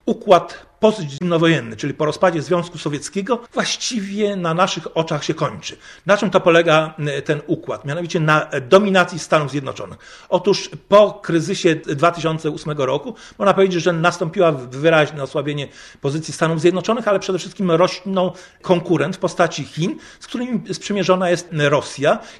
Były polityk ZChN, a obecnie Prawicy Rzeczpospolitej, Marian Piłka wygłosił w Poznaniu wykład "Czy grozi nam wojna ?".